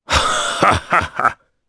Kaulah-Vox-Laugh_jp.wav